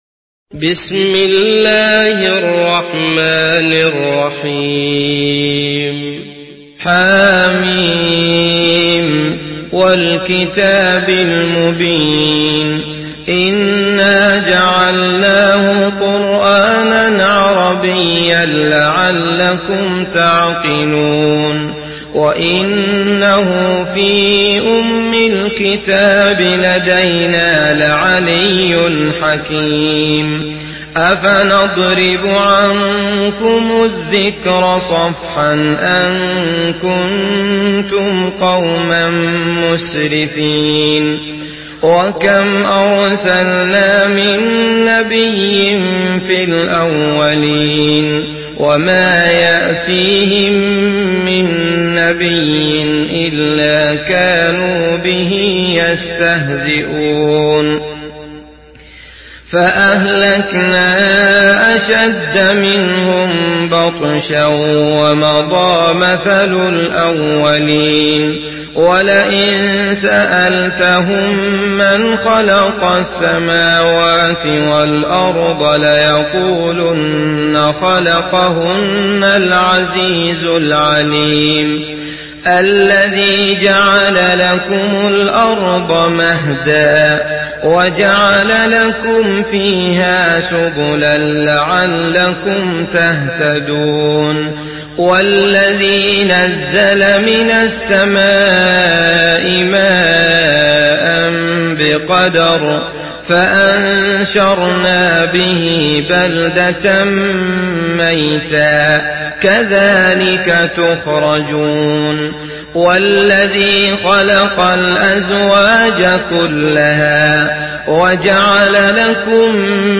Surah আয-যুখরুফ MP3 by Abdullah Al Matrood in Hafs An Asim narration.
Murattal Hafs An Asim